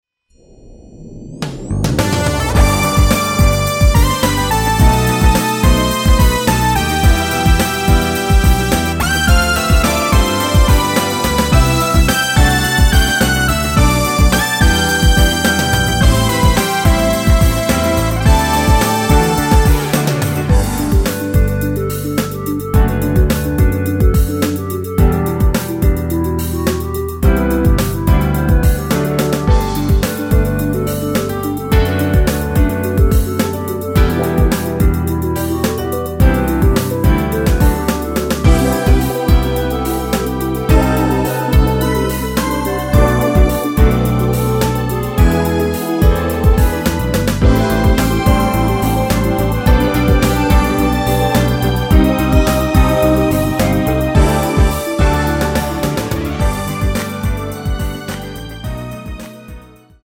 (-1) 내린 멜로디 포함된 MR 입니다.(미리듣기 참조)
◈ 곡명 옆 (-1)은 반음 내림, (+1)은 반음 올림 입니다.
앞부분30초, 뒷부분30초씩 편집해서 올려 드리고 있습니다.